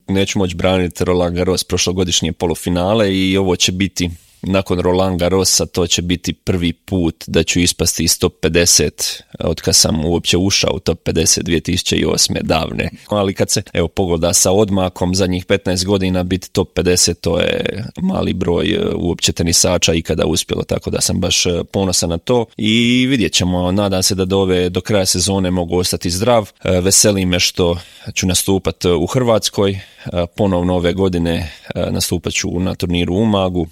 Kako je došao na ideju osnivanja zaklade, Marin je u intervjuu Media servisa rekao: